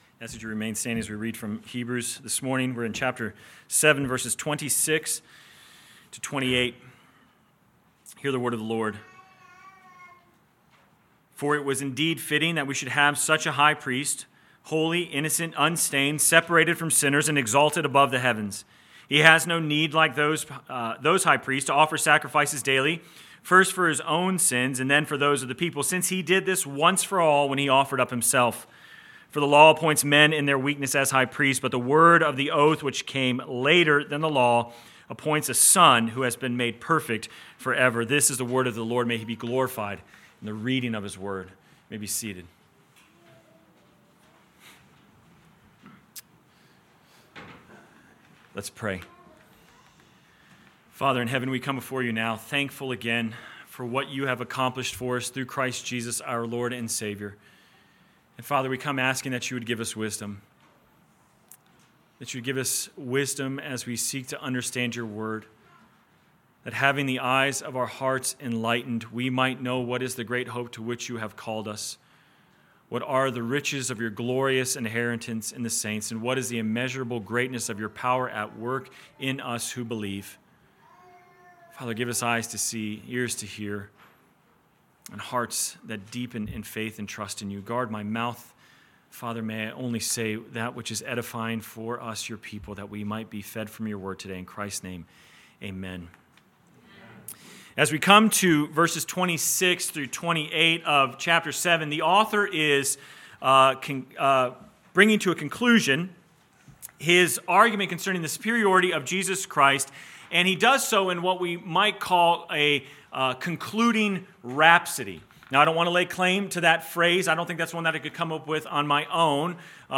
Sermon Text: Hebrews 7:26-28 First Reading: Leviticus 19:2 Second Reading: Romans 4:13-24